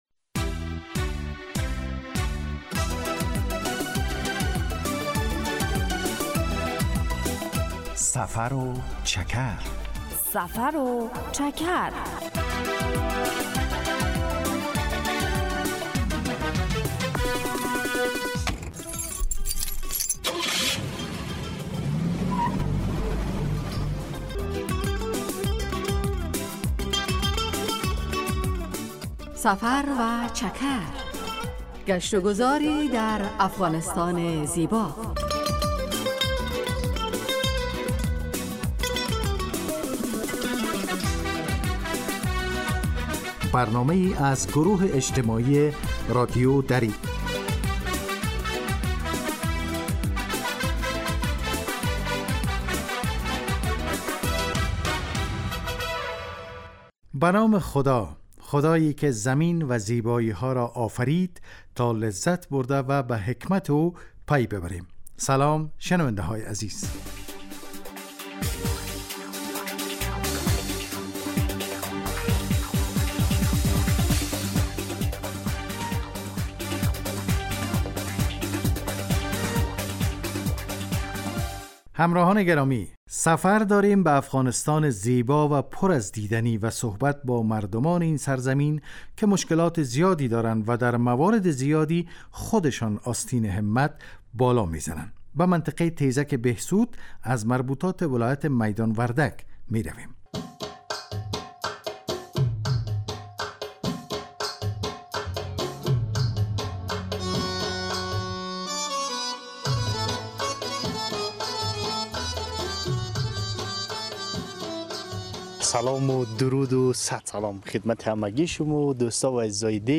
هدف: آشنایی با فرهنگ عامه و جغرافیای شهری و روستایی افغانستان که معلومات مفید را در قالب گزارش و گفتگو های جالب و آهنگ های متناسب تقدیم می کند.